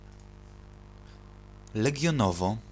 Legionowo [lɛɡʲjɔˈnɔvɔ]
Pl-Legionowo.ogg.mp3